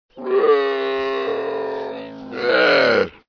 Верблюд издает звуки будто хочет говорить